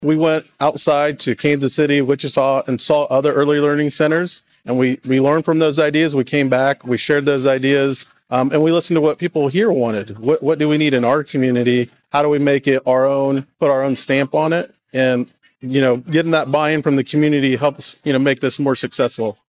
A ribbon-cutting ceremony was held at the new Jones Early Childhood Development Center culminating a year and a half’s worth of work that began back in early November of 2020. USD 253 Board of Education President Art Guittierez says the district used a combination of local input and outside inspiration to create the new facility.